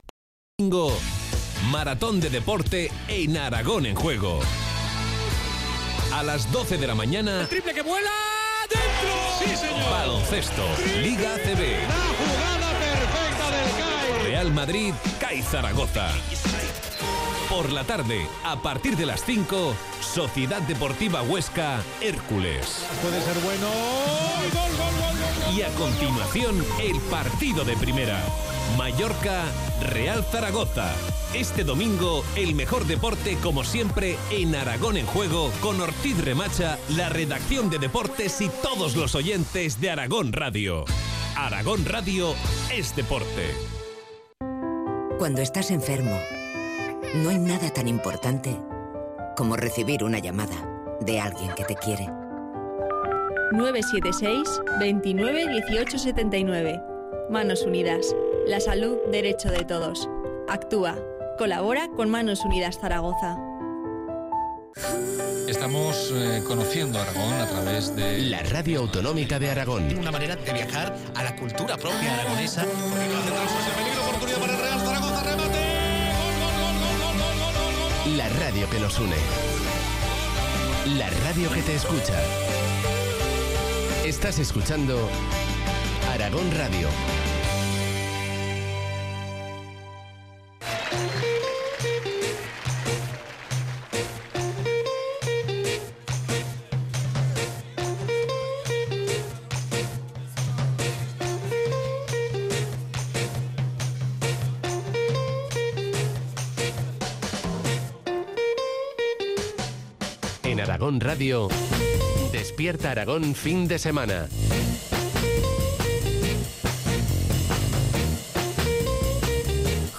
Podcast Intervención en el programa «Despierta Aragón», de Radio Aragón. ¿Es importante la primera impresión?